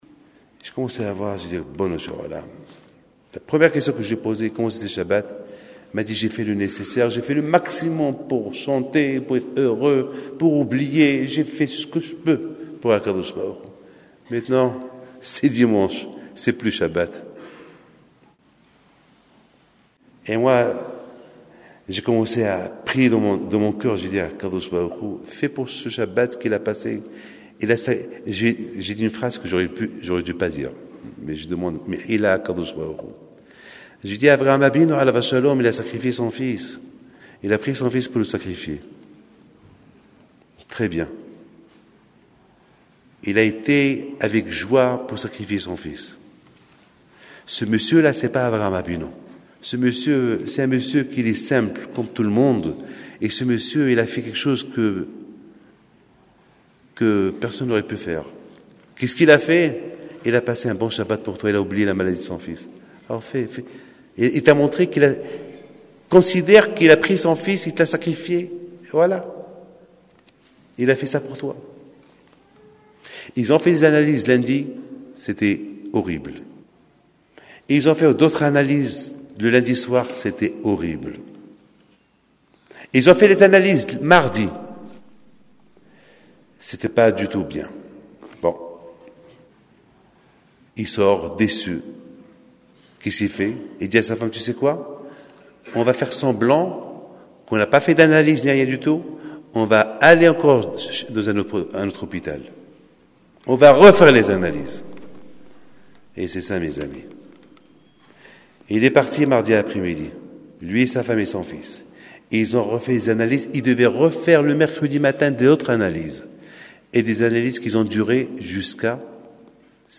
à la grande synagogue de la rue Buffault à Paris